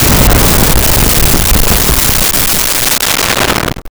Explosion 04
Explosion 04.wav